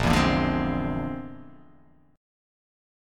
A#9b5 chord